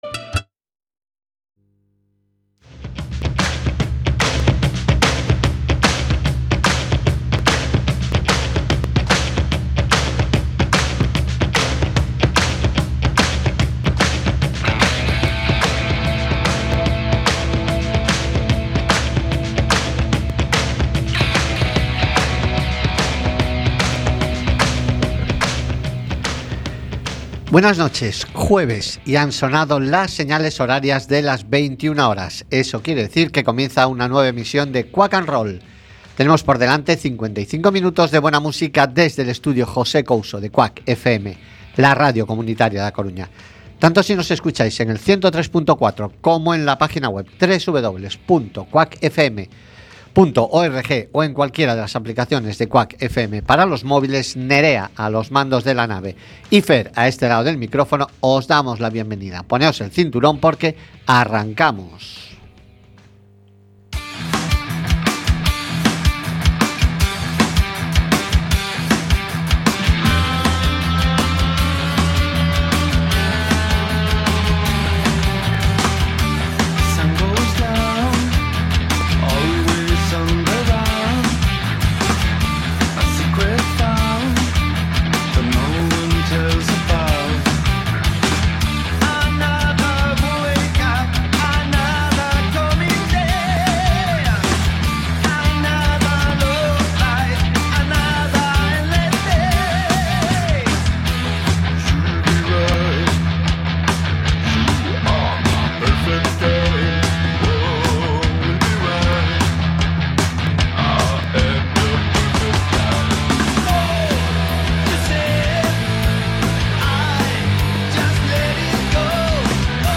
Programa musical que huye de las radioformulas y en el que podreis escuchar diversidad de generos... Rock , Blues , Country, Soul , Folk , Punk , Heavy Metal , AOR...